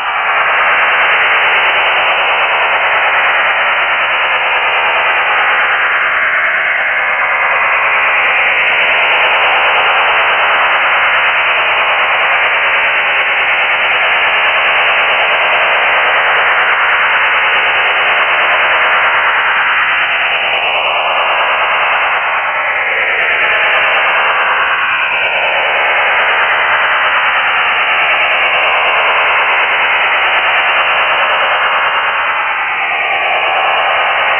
STANAG4285
Начало » Записи » Радиоcигналы классифицированные